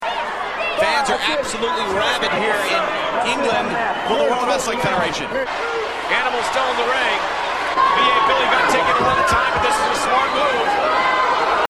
crowd abruptly switches from loud to quiet and back to loud again.